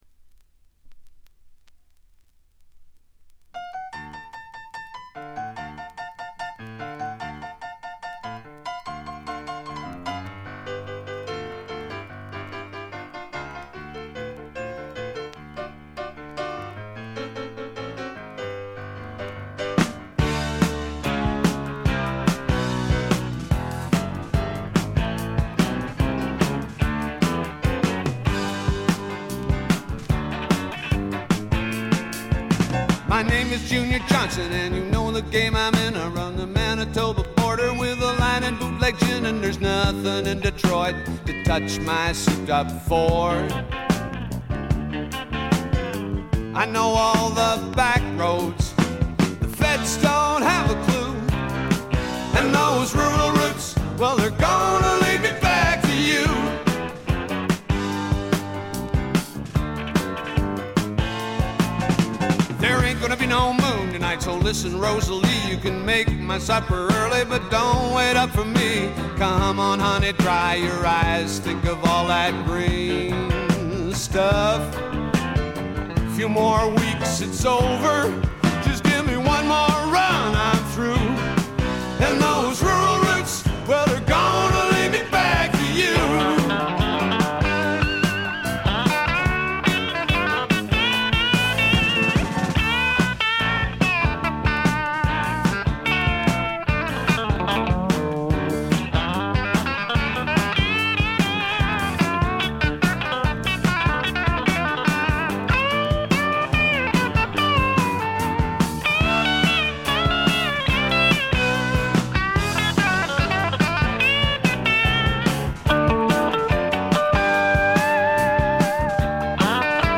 静音部で軽微なチリプチ。散発的なプツ音少し。
思い切り泥臭さいサウンドなのにポップでキャッチーというのも素晴らしい。
試聴曲は現品からの取り込み音源です。